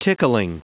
Prononciation du mot tickling en anglais (fichier audio)
Prononciation du mot : tickling